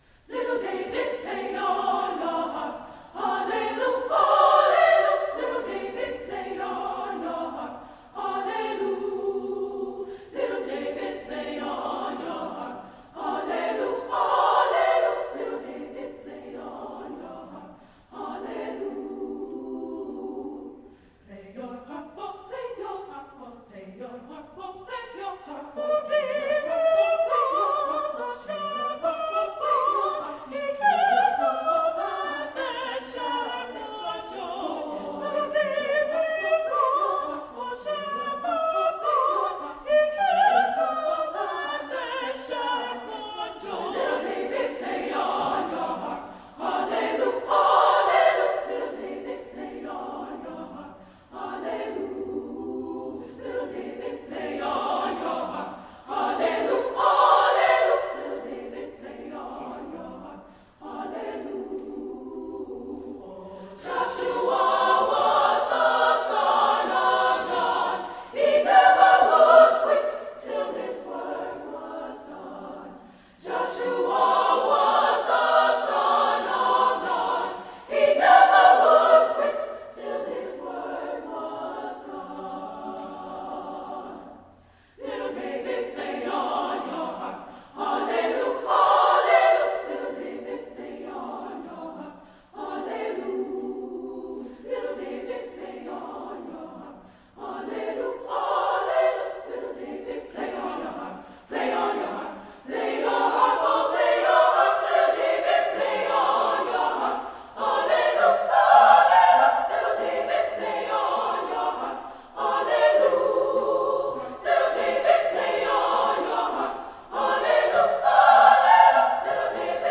Voicing: SSAA
Instrumentation: a cappella